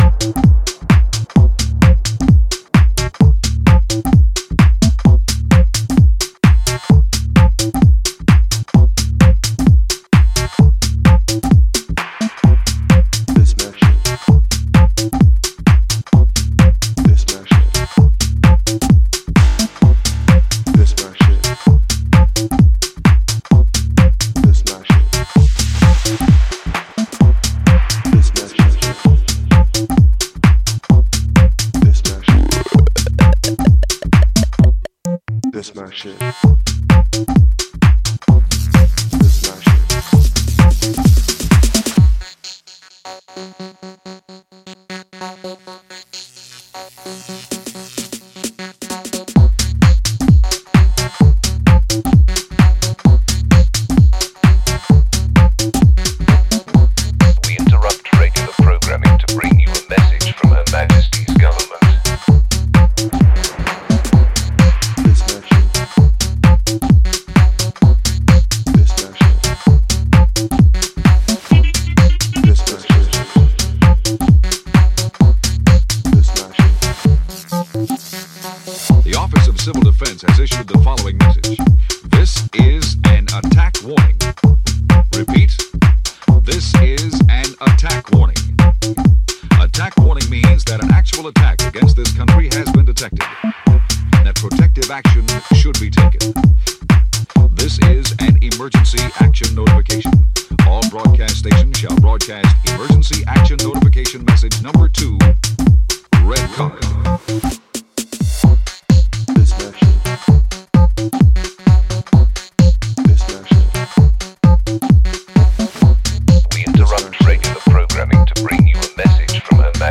rock-solid house cuts designed for maximum club impact
rubbery tripped-out tech